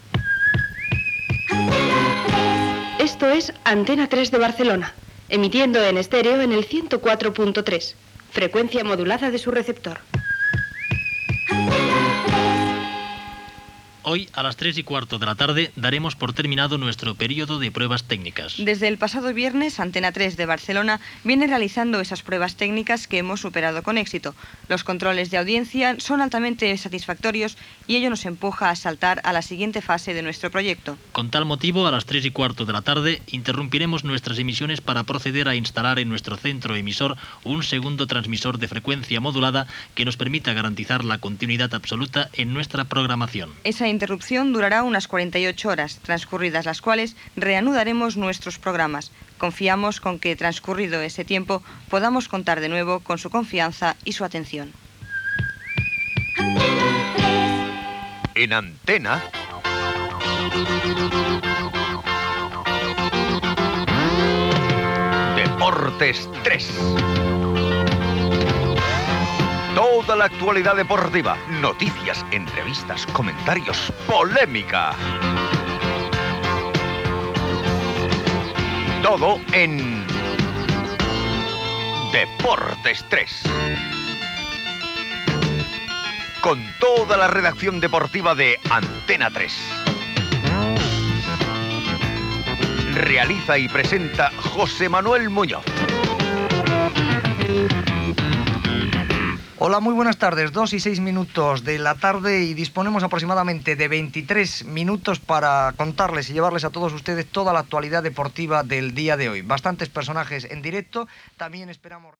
Indicatiu de l'emissora, identificació, avís de l'aturada de l'emissió per instal·lar un segon transmissor i careta "Deportes 3".
Esportiu
FM